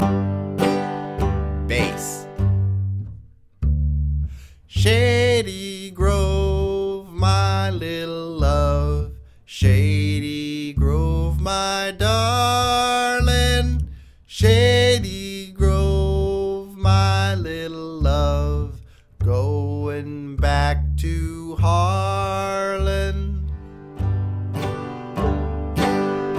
Rhythm: Banjo